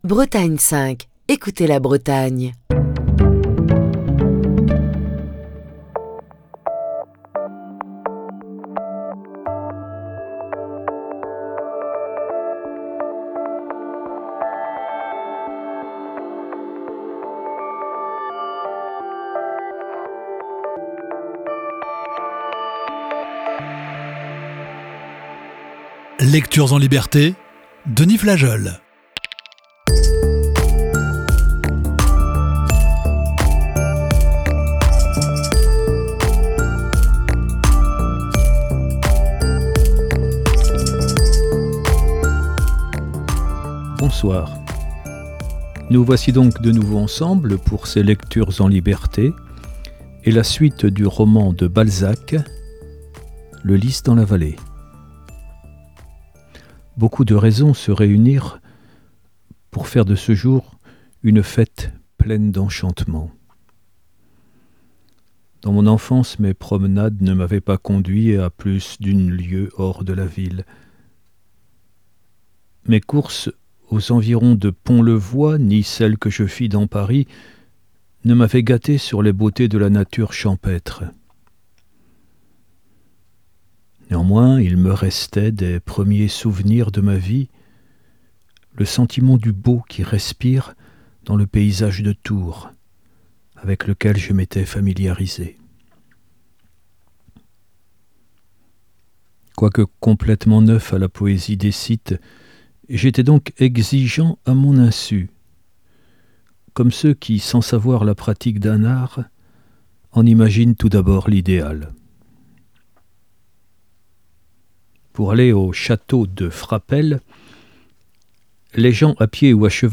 Émission du 18 octobre 2023.